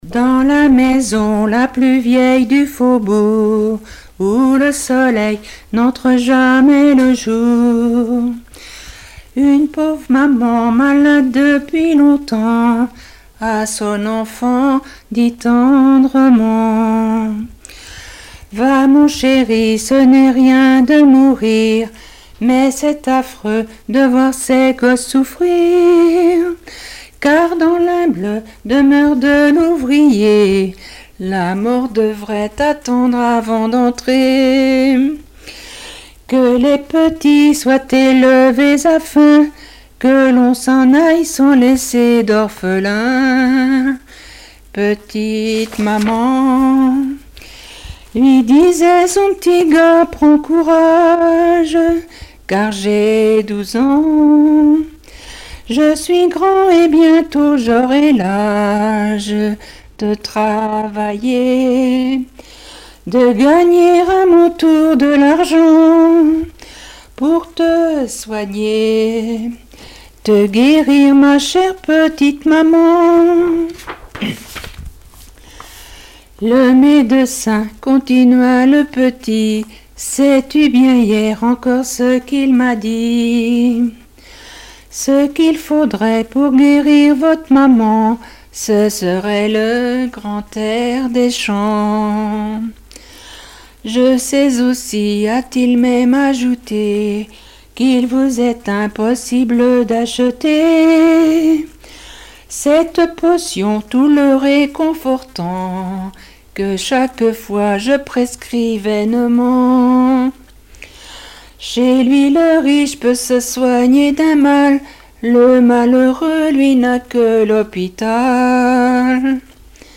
Genre strophique
Chansons et témoignages
Catégorie Pièce musicale inédite